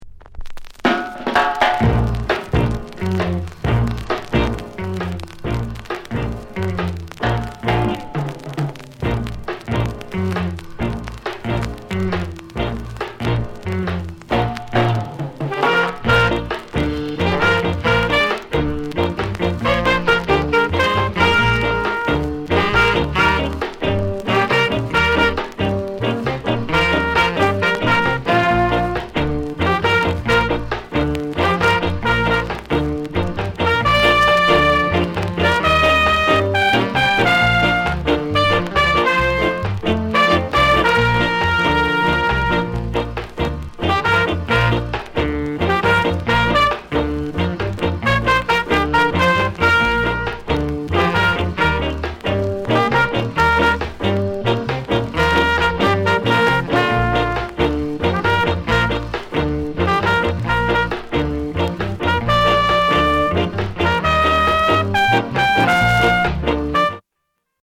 SKA INST